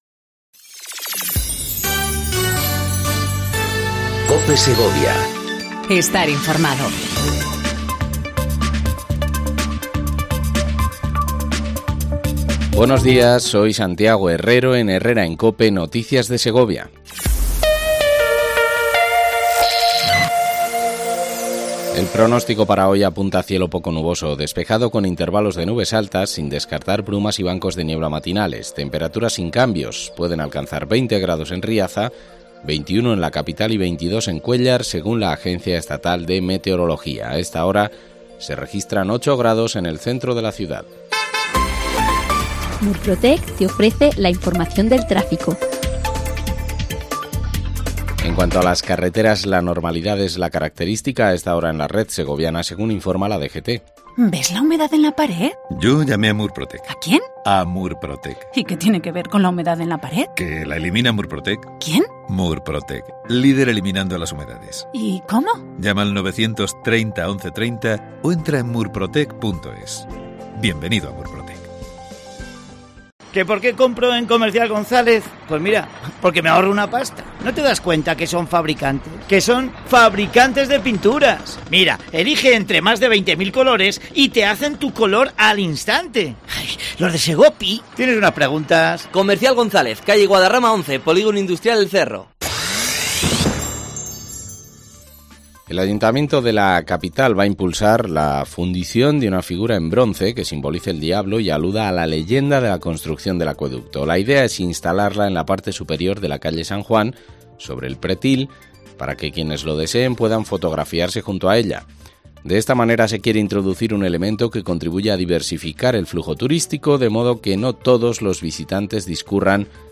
AUDIO: Segundo informativo local en cope segovia